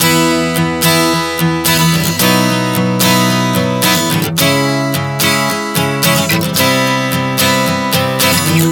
Prog 110 E-B-C#m-C.wav